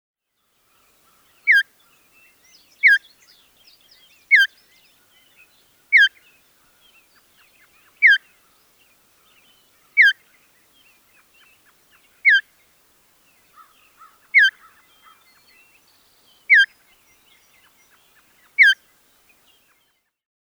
Звуки пересмешника
Позывной сигнал